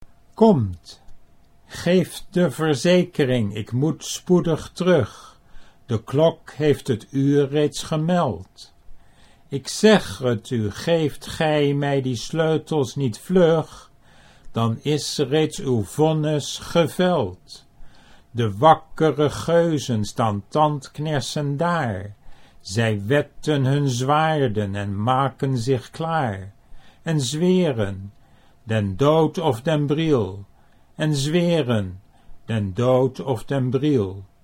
Reading the poem aloud, its rhythm came naturally, without special effort - but it's not how people in Holland normally speak.